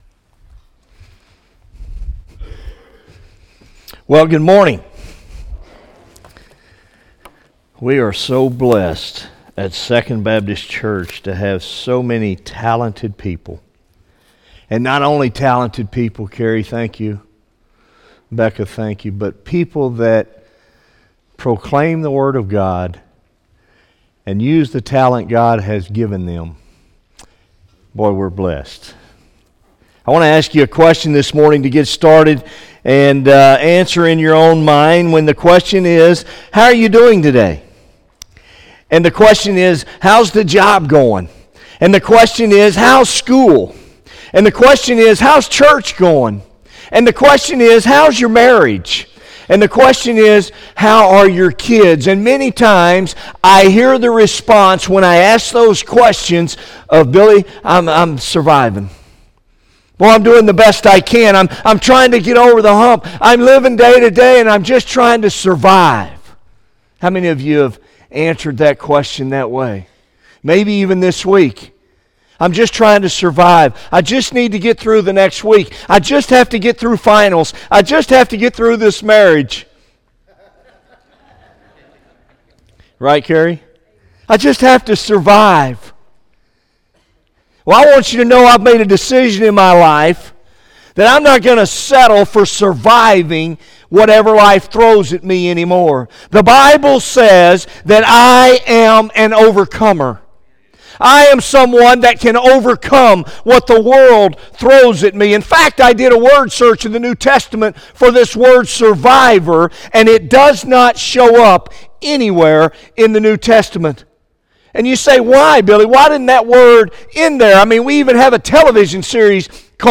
by Office Manager | Dec 19, 2016 | Bulletin, Sermons | 0 comments